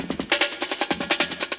61 LOOP01.wav